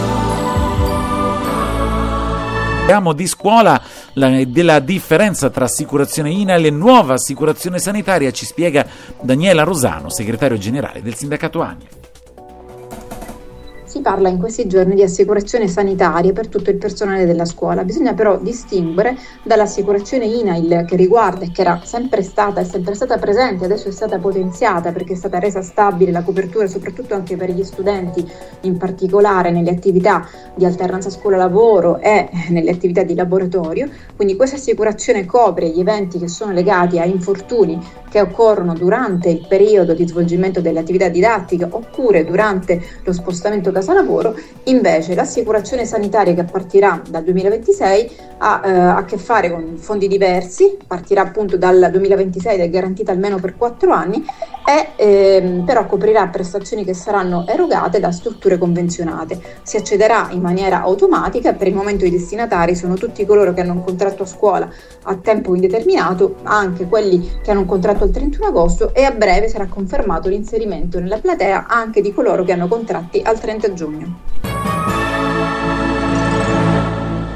Nel corso della diretta, la professoressa ha chiarito i dubbi e parlato delle proposte del sindacato.